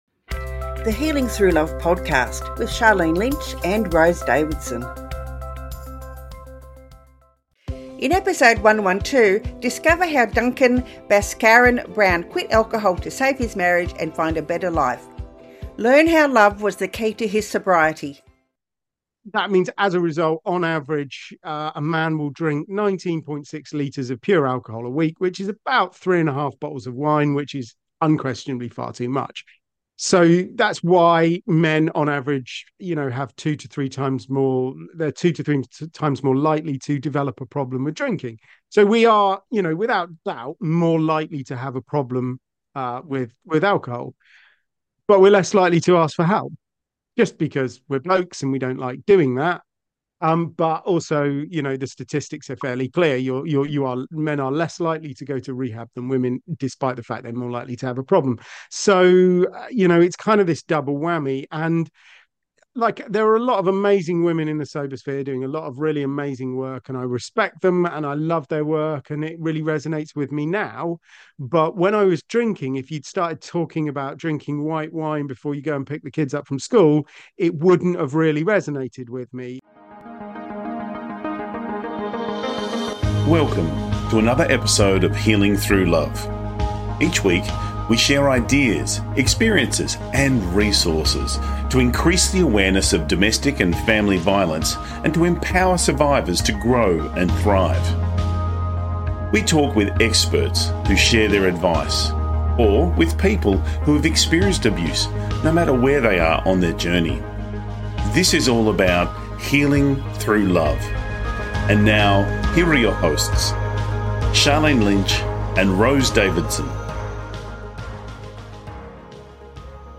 Key Points from the Interview: How quitting alcohol can positively transform relationships and improve personal connections. The role of love as a powerful motivator in the journey to sobriety and personal growth. The unexpected benefits of living alcohol-free, including increased energy and productivity.